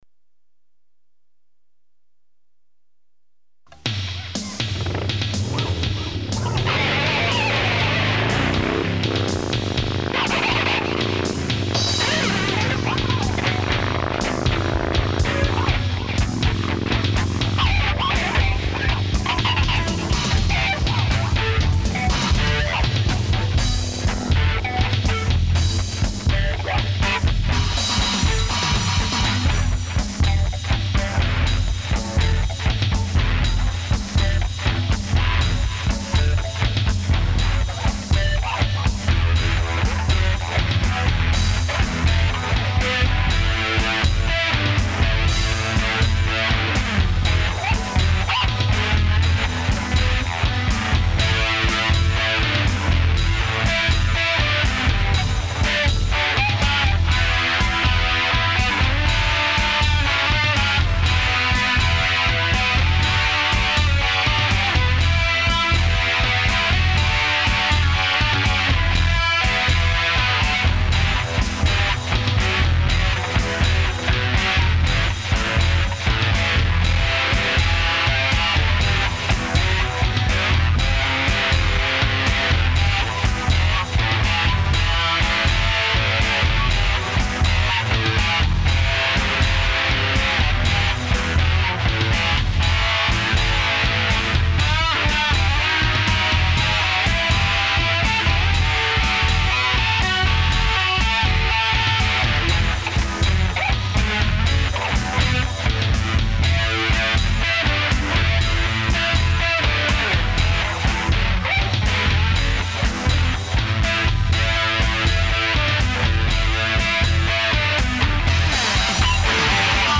It is kind of far out there with all the weird riffs and motorcycle sounds.
Over five minutes long and about 9.5 Mb, full of weird stuff.